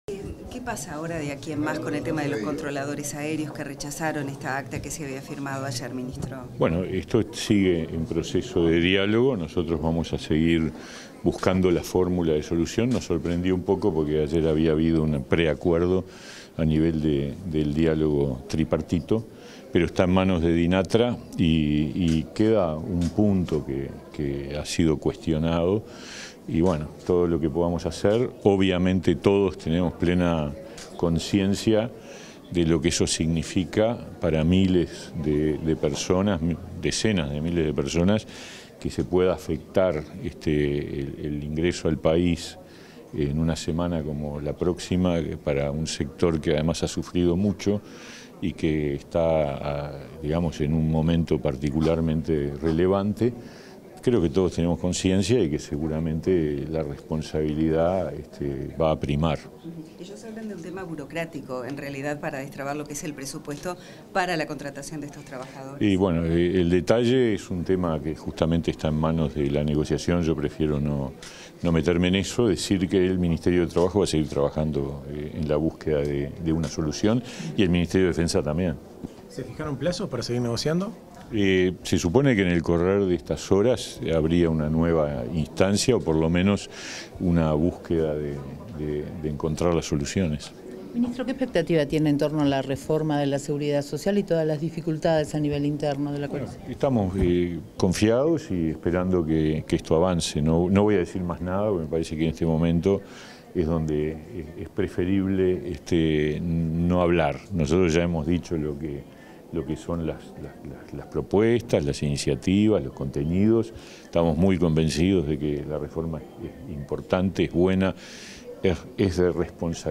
Declaraciones a la prensa del ministro de Trabajo y Seguridad Social, Pablo Mieres
Declaraciones a la prensa del ministro de Trabajo y Seguridad Social, Pablo Mieres 30/03/2023 Compartir Facebook X Copiar enlace WhatsApp LinkedIn Tras el lanzamiento de un manual de prevención de la violencia basada en género en el ámbito laboral, este 30 de marzo, el ministro de Trabajo y Seguridad Social, Pablo Mieres, realizó declaraciones a la prensa.